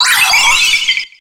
Cri de Créfadet dans Pokémon X et Y.